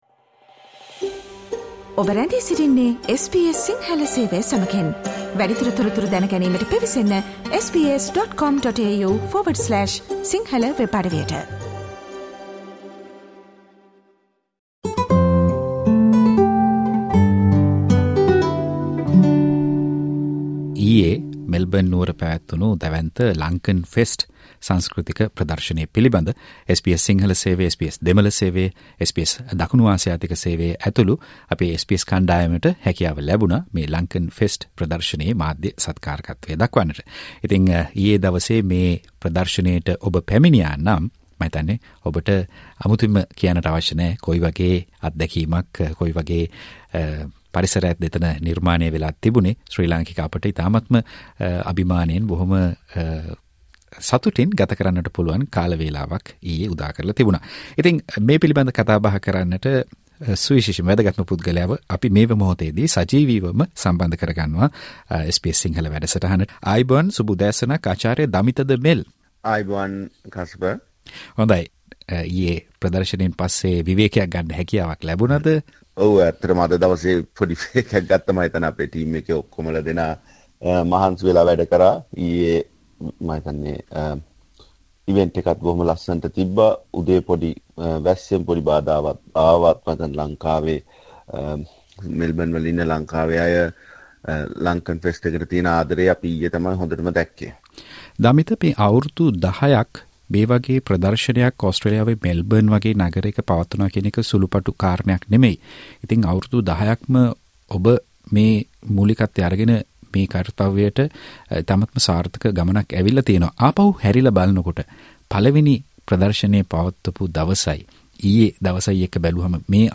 SBS සිංහල සජීව ගුවන්විදුලි වැඩසටහනට